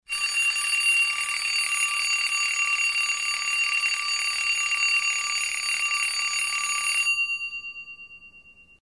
Звук советского будильника